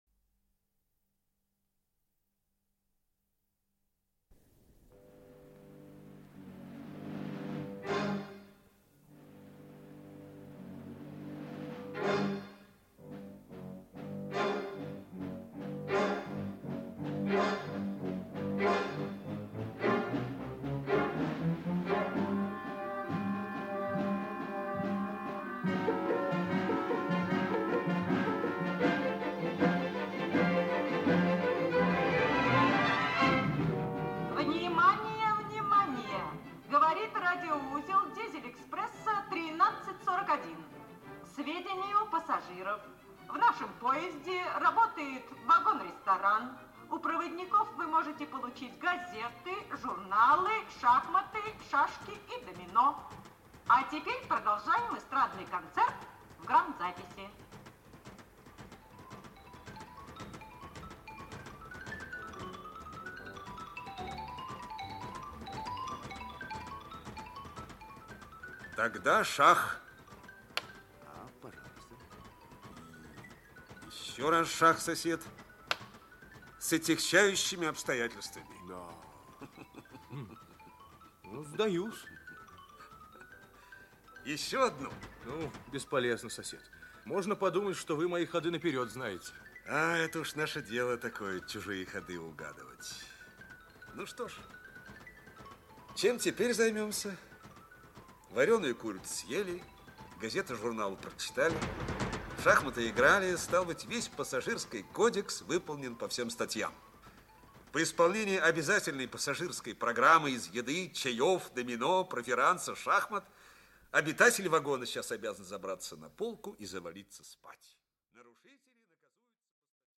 Аудиокнига Дело, которого не было | Библиотека аудиокниг
Aудиокнига Дело, которого не было Автор Александр Дитрих Читает аудиокнигу Актерский коллектив.